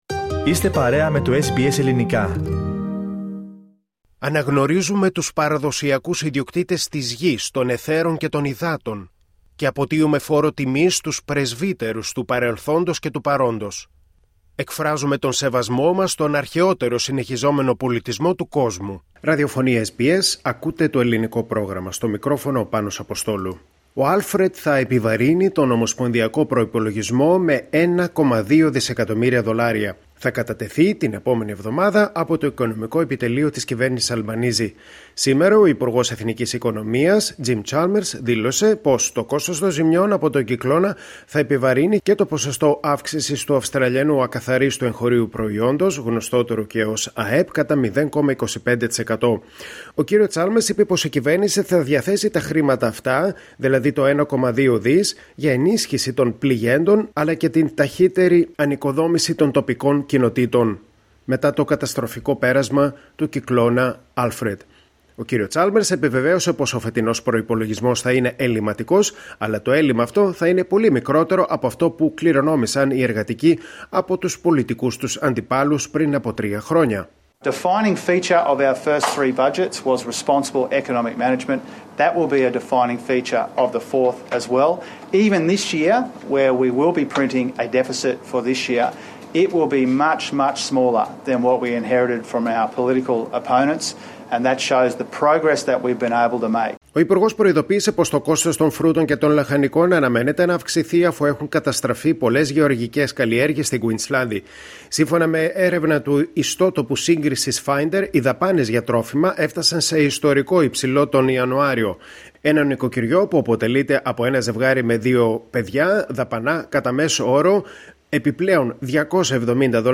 Το κόστος των ζημιών που άφησε πίσω του ο τροπικός κυκλώνας Άλφρεντ θα έχει αντίκτυπο και στον ομοσπονδιακό προϋπολογισμό. Ο υπουργός Εθνικής Οικονομίας Τζιμ Τσάμερς έδωσε σήμερα συνέντευξη Τύπου και αναφέρθηκε και στην συμφωνία AUKUS για τα πυρηνικά υποβρύχια
Treasurer Jim Chalmers speaks to the media during a press conference in Brisbane, Monday, March 17, 2025.